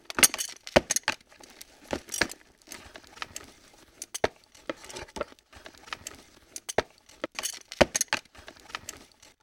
c4_disarm.mp3